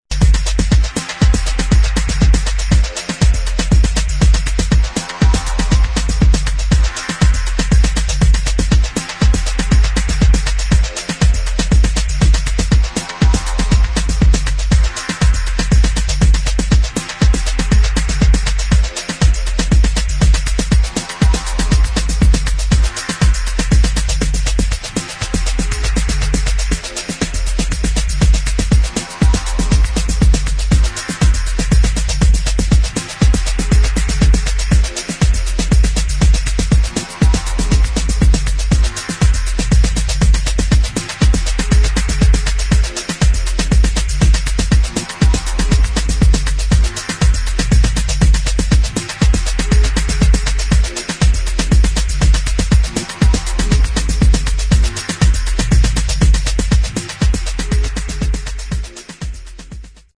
[ TECHNO / ELECTRONIC ]